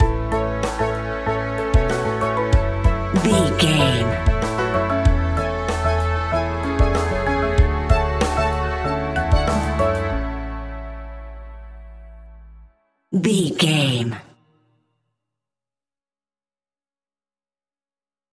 Ionian/Major
pop rock
energetic
uplifting
instrumentals
upbeat
groovy
guitars
bass
drums
piano
organ